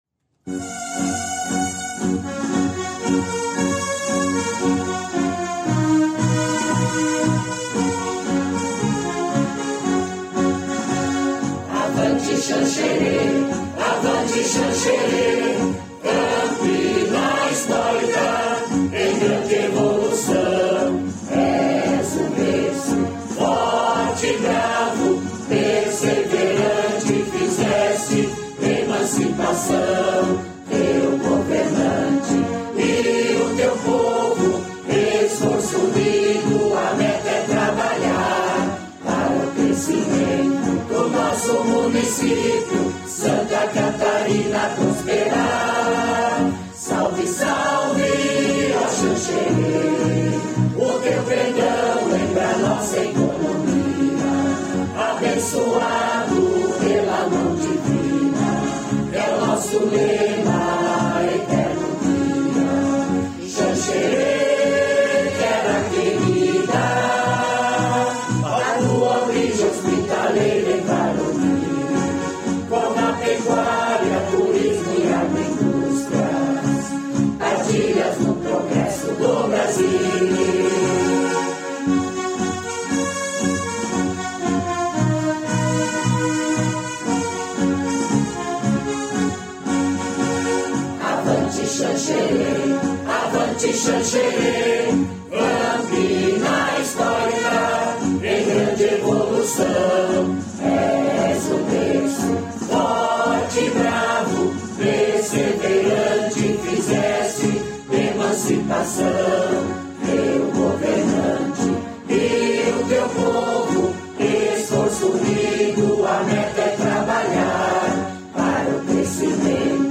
Hino-Xanxere.mp3